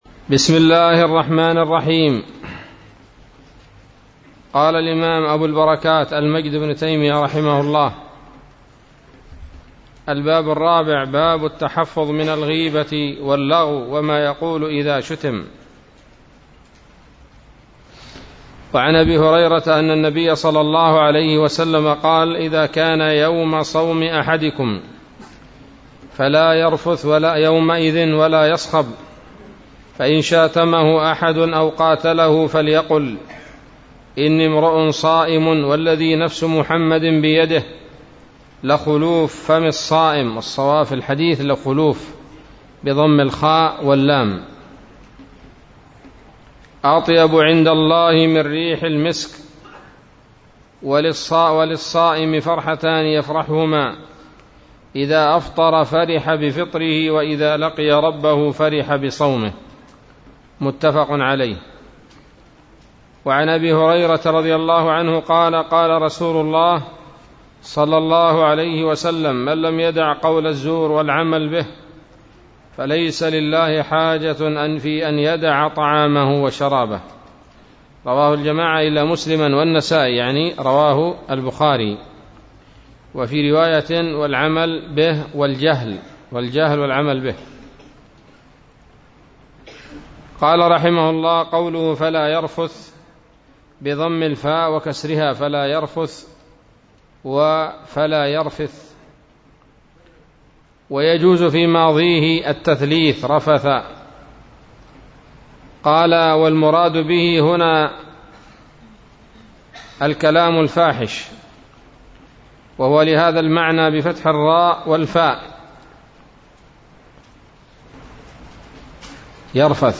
الدرس الثالث عشر من كتاب الصيام من نيل الأوطار